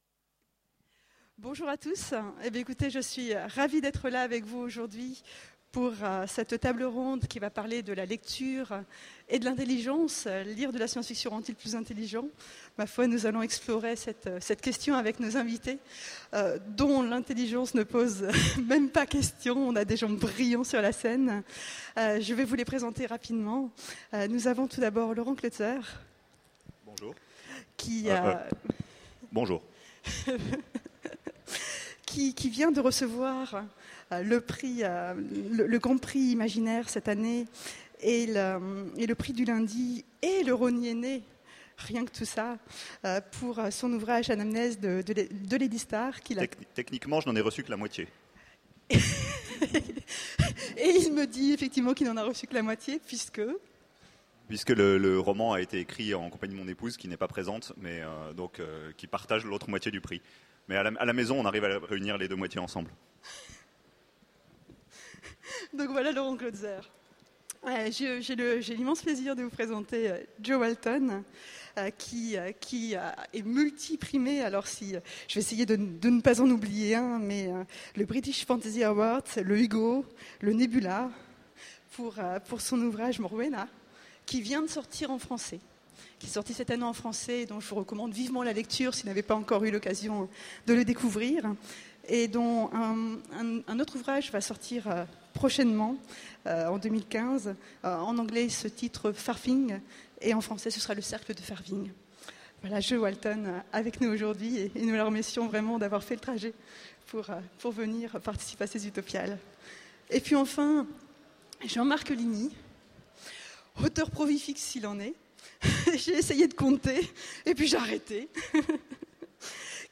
Mots-clés SF Conférence Partager cet article